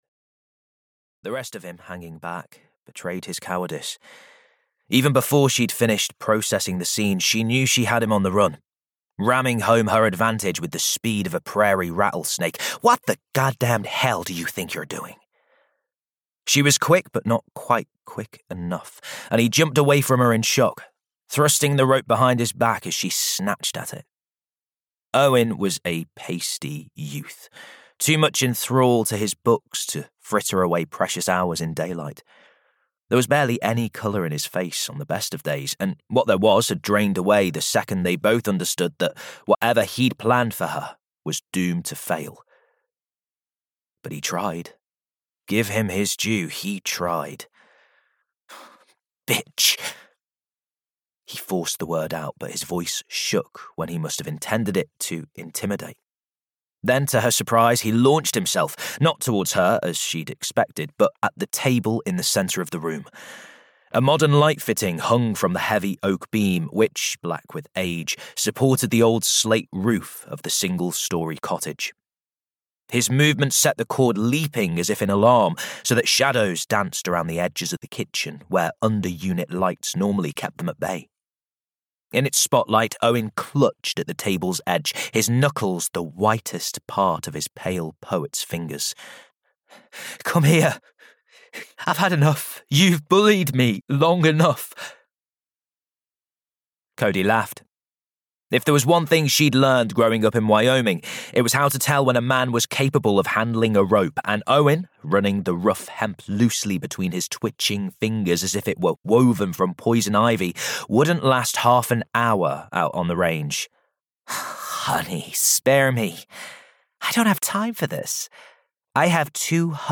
Death on Coffin Lane (EN) audiokniha
Ukázka z knihy